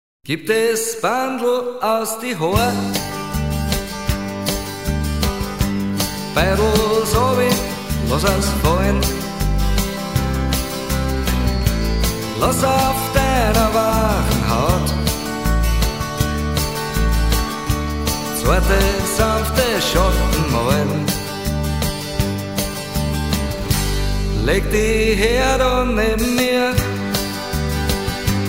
Gattung: Moderne Blasmusik
Besetzung: Blasorchester
Stil: Country-Beat
Tonart: F-Dur